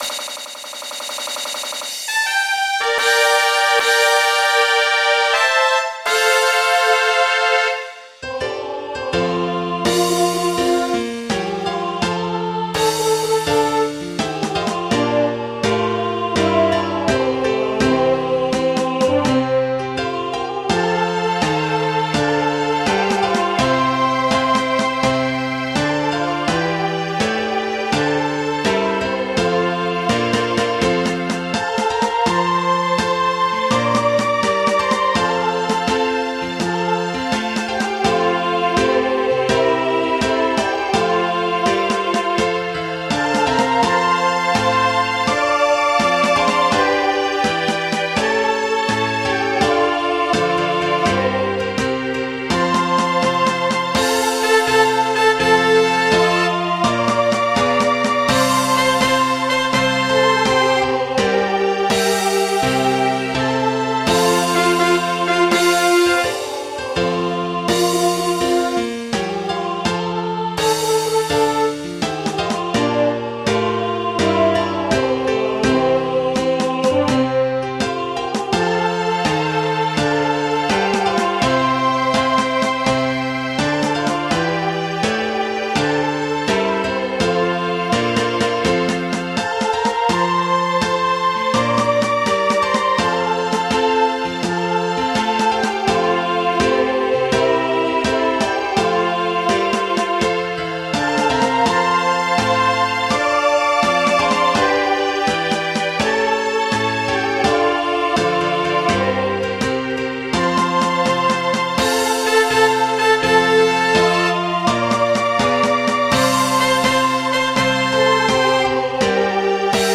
MIDI 16.45 KB MP3 (Converted) 2.01 MB MIDI-XML Sheet Music